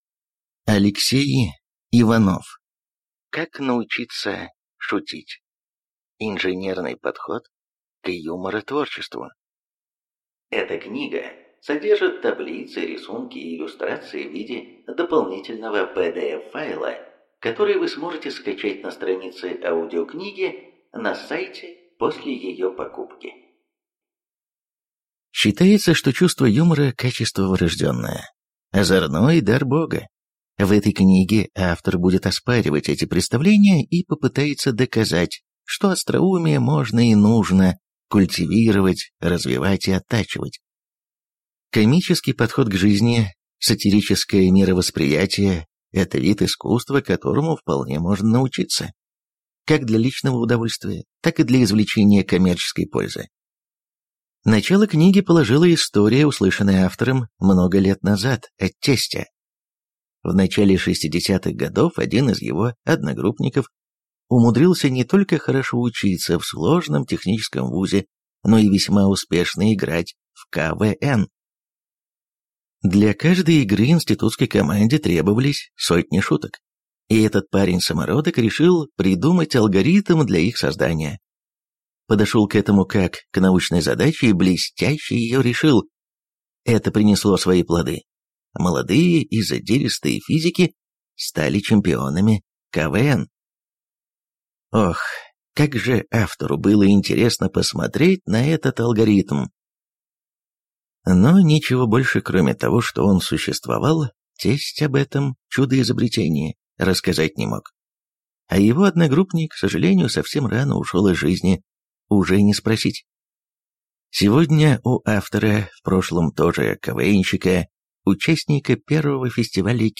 Аудиокнига Как научиться шутить. Инженерный подход к юморотворчеству | Библиотека аудиокниг